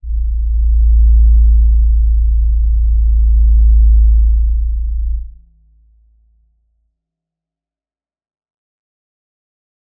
G_Crystal-A1-mf.wav